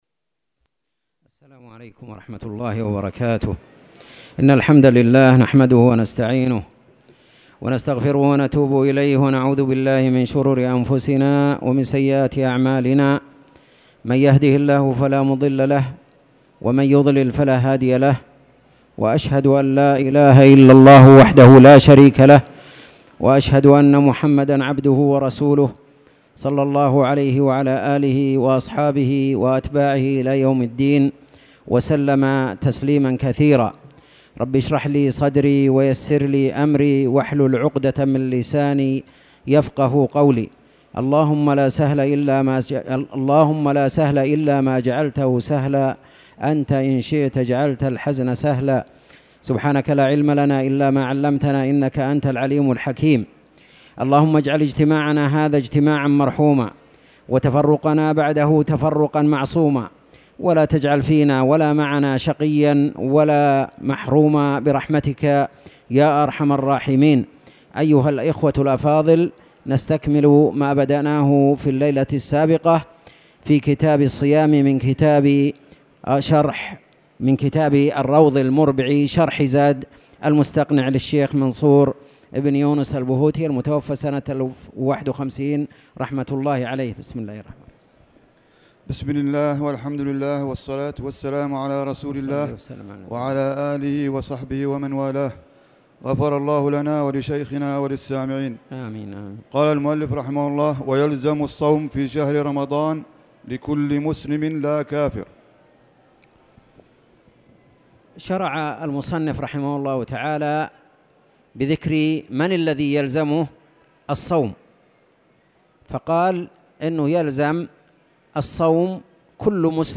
تاريخ النشر ٧ ربيع الأول ١٤٣٨ هـ المكان: المسجد الحرام الشيخ